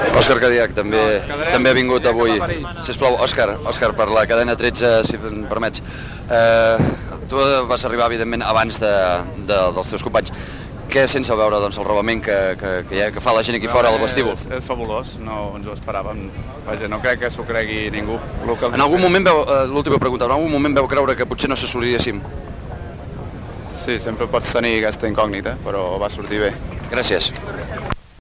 Connexió amb el vestíbul de l'aeroport del Prat de Barcelona, una hora abans de l'arribada de l'expedició catalana a l'Everest. Descripció de l'ambient i entrevistes informatives a algunes persones que s'hi congreguen.
Informatiu